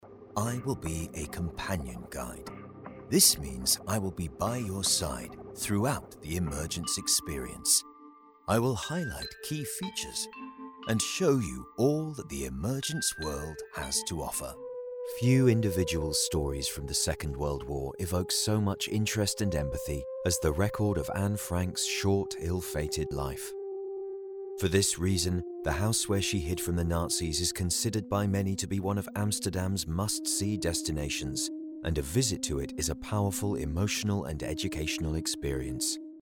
Inglés (Británico)
Comercial, Profundo, Versátil, Cálida, Empresarial
Audioguía